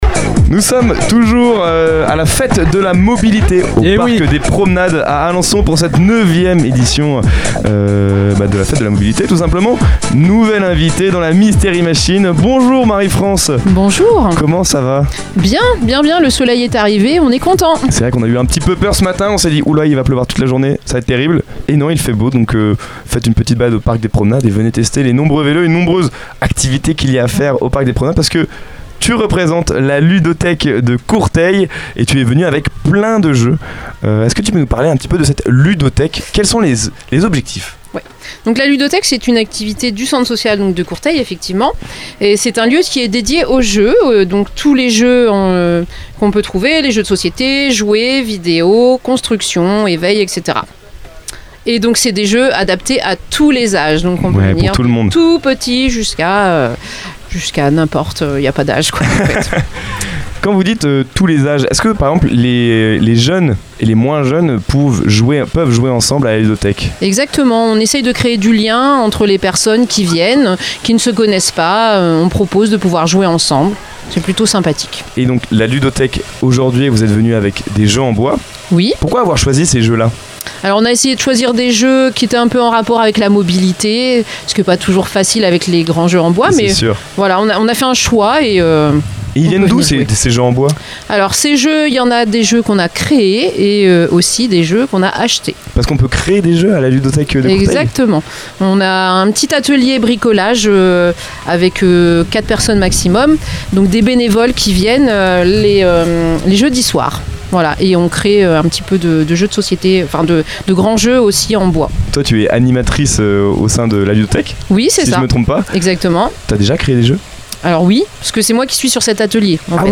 À l’occasion de la Fête de la Mobilité au Parc des Promenades à Alençon, nous étions en direct depuis la Mystery Machine (studio radio aménagé dans une camionnette).